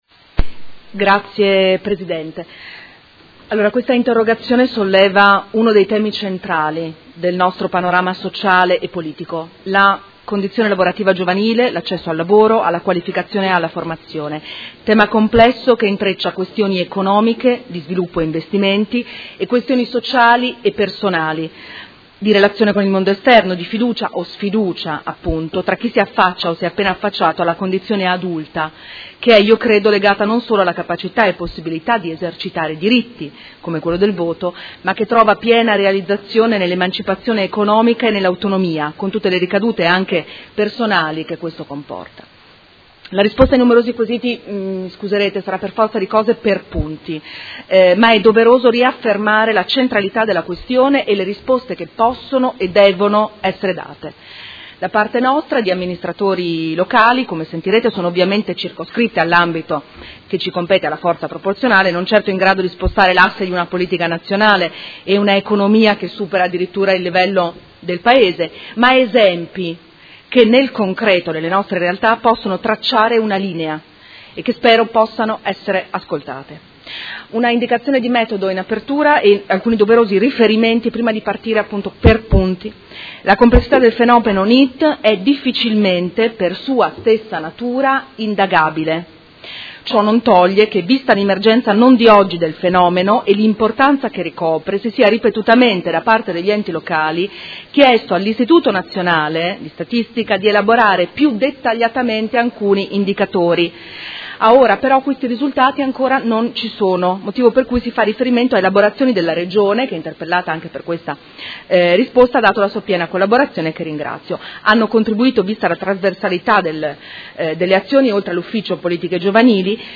Risponde a interrogazione della Consigliera Venturelli (PD) avente per oggetto: I NEET e la povertà giovanile: giovani che non lavorano e non studiano.